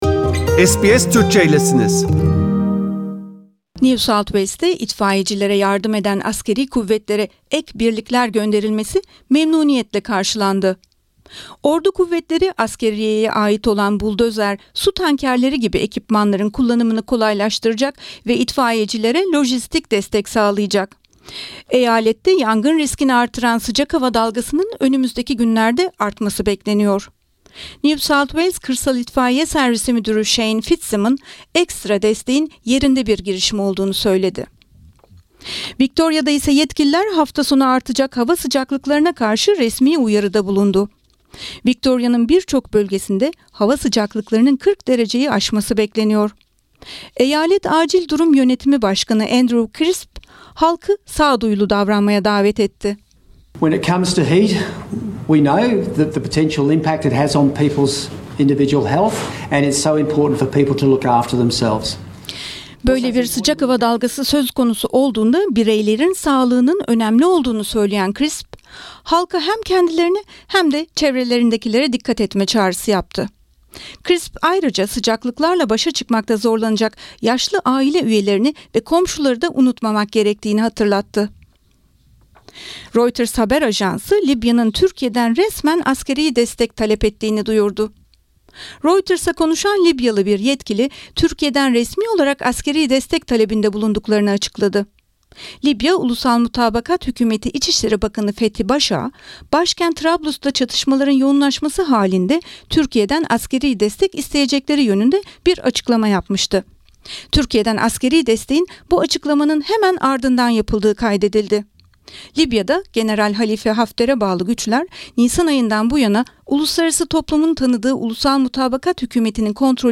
SBS Türkçe haberler